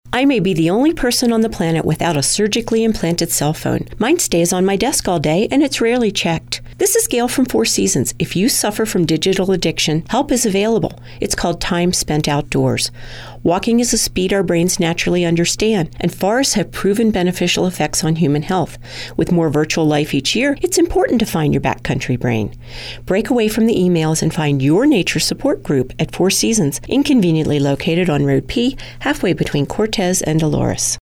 Examples of Radio Ads